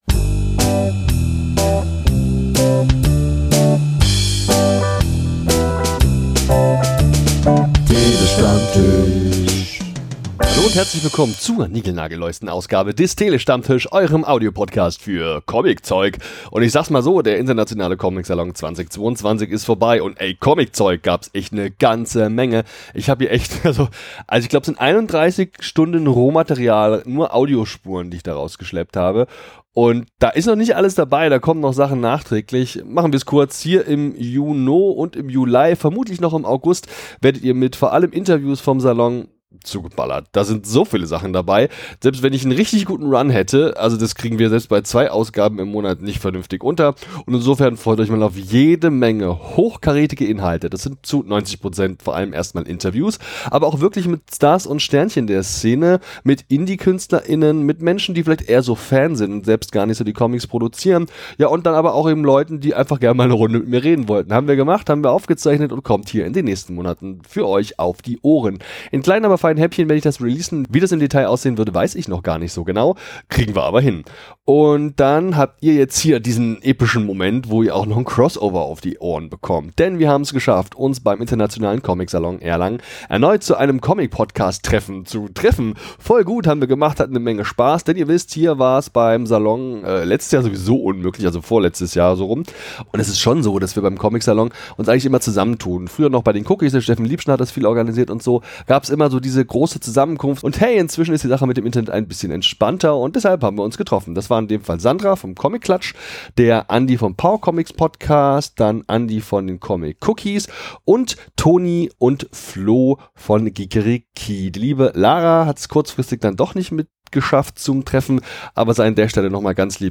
Aufzeichnung des traditionellen Comicpodcast-Treffen beim Internationalen Comic-Salon Erlangen 2022 Es ist inzwischen eine Tradition, dass sich alle auf dem Internationalen Comic-Salon Erlangen anwesenden Podcaster:innen zu einem großen Crossover zusammen tun.